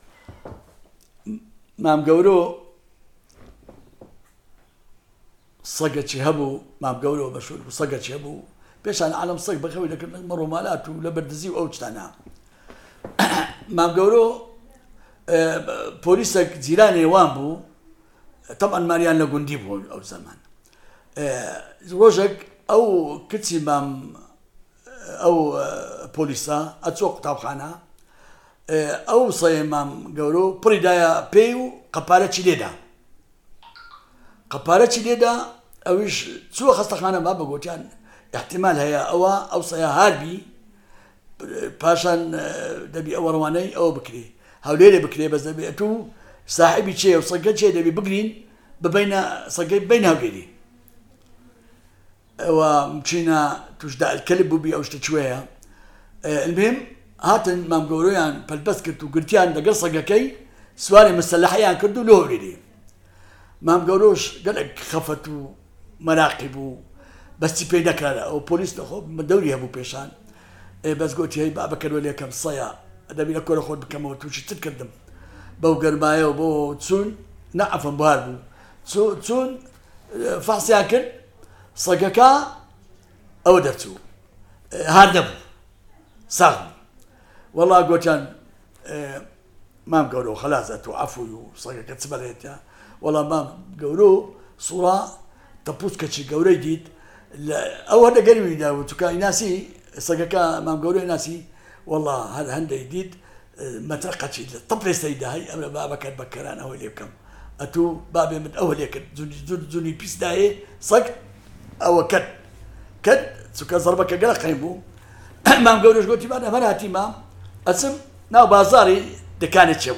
The Kurdish and Gorani Dialect Database